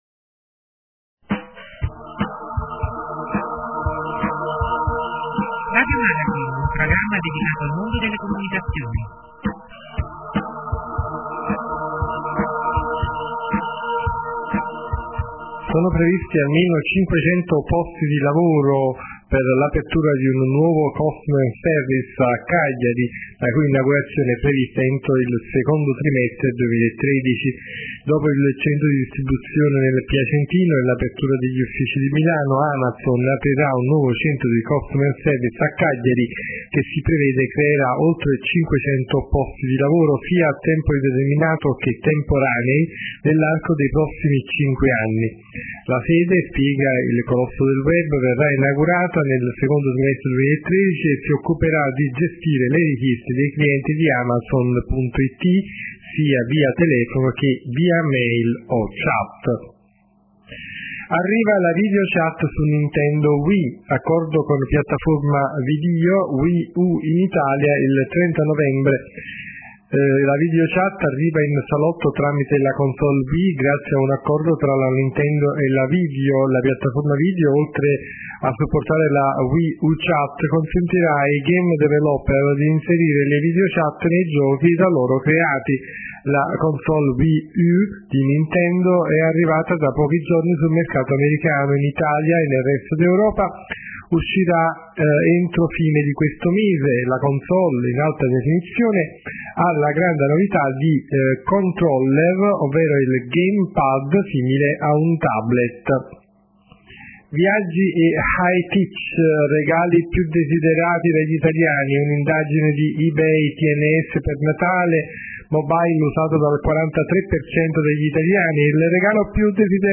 notizie dal mondo delle comunicazioni